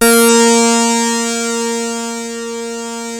OSCAR A#4 2.wav